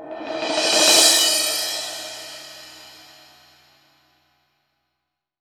03 CYMBAL SW.wav